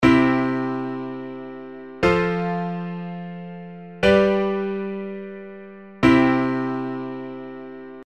↓の音源は、3コードでの進行（I・IV・V・I）、すなわちT⇒SD⇒D⇒Tというコード進行です。
CFGCのコード進行
Dで縮こまり、最後のTで落ち着いた感じがしますね。